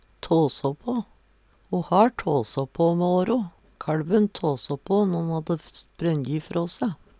tåså på - Numedalsmål (en-US)
DIALEKTORD PÅ NORMERT NORSK tåså på minke, bli rolegare, mindre aktiv Eksempel på bruk Ho har tåså på mæ åro.